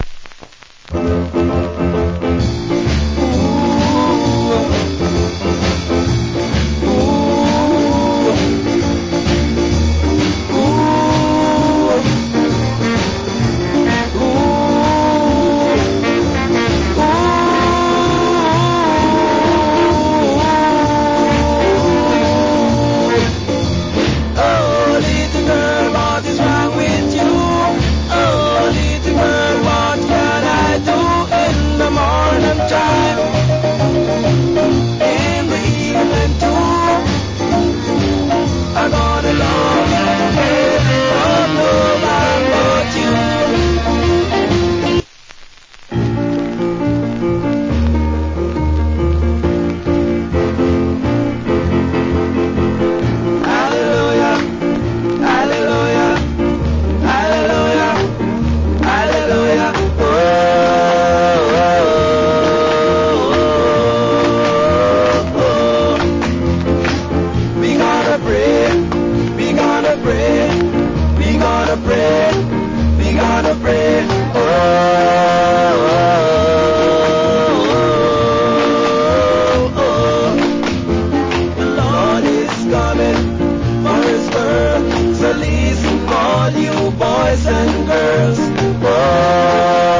Condition VG(OK,HISS,LD,WOL)
Great Shuffle Duet Vocal. / Nice Jamaican R&B Vocal.